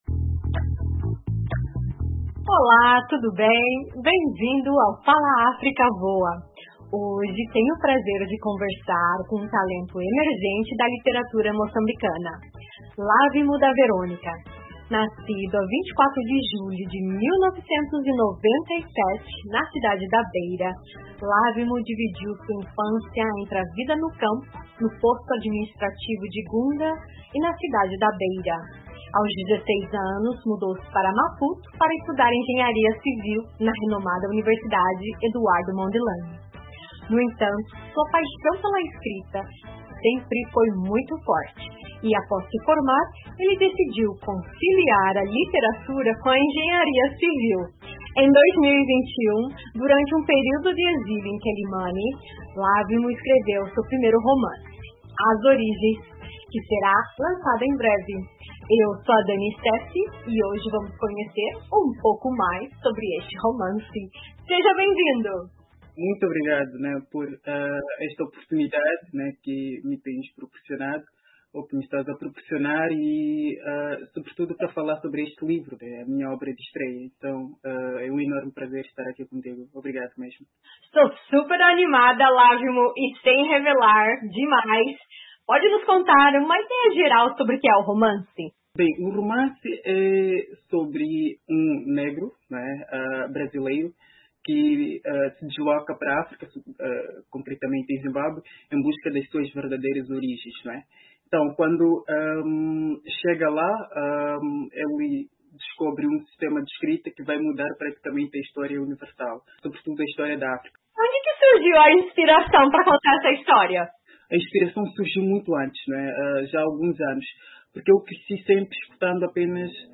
Nesta entrevista, o autor partilha a sua jornada criativa, as inspirações por trás da obra e os desafios enfrentados ao transformar ideias em palavras.